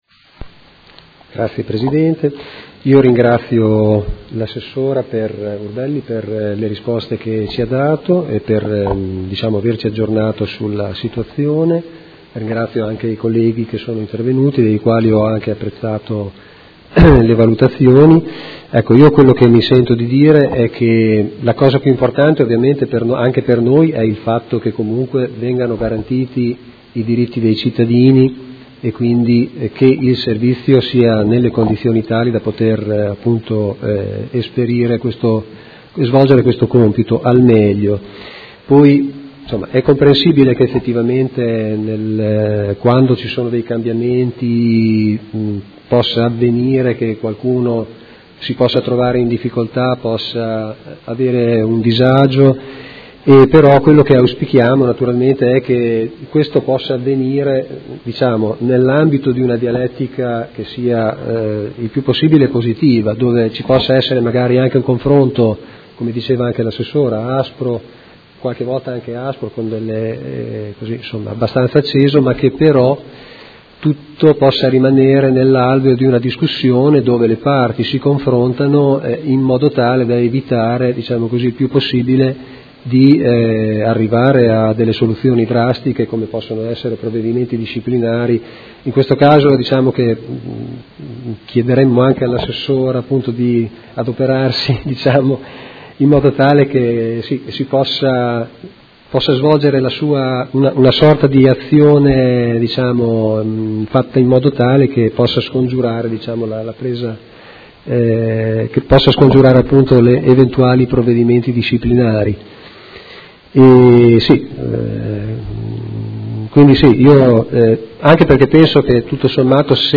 Seduta del 15/06/2017. Dibattito su interrogazione dei Consiglieri Malferrari e Cugusi (Art.1-MDP) avente per oggetto: Contestazioni disciplinari dell’AUSL di Modena a medici del servizio di continuità assistenziale